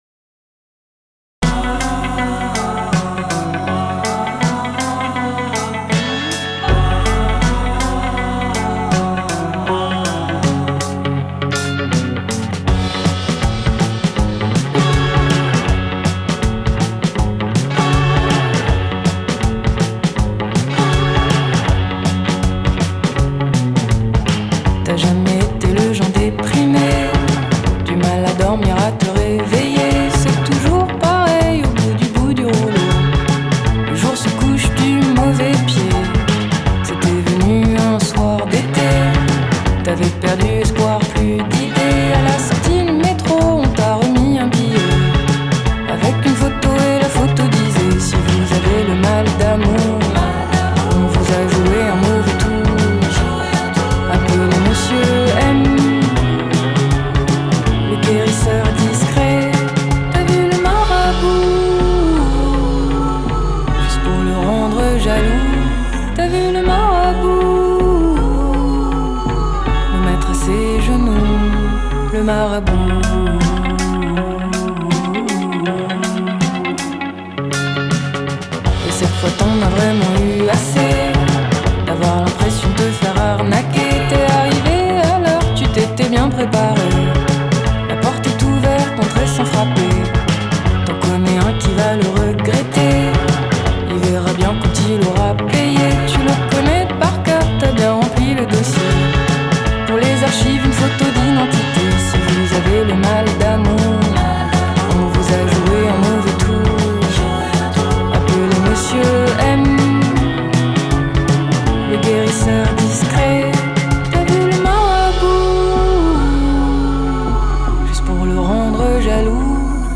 Genre: World, French Pop, Indie, Chanson